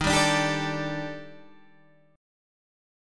D#M#11 chord